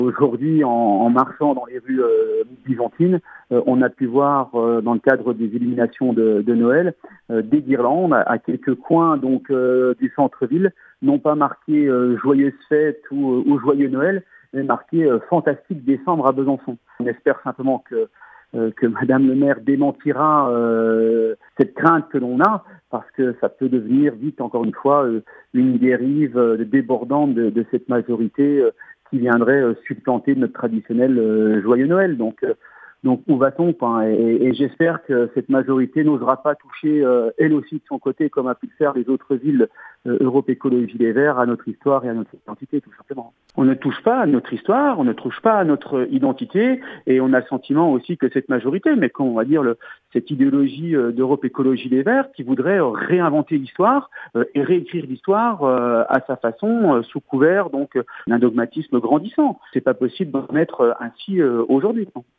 Au conseil municipal de Besançon, l’opposant Ludovic Fagaut fustige l’initiative des commerçants de Besançon, ville dirigée par une écologiste, de remplacer « Bonnes fêtes » et « Joyeux Noël » par « Fantastique décembre ».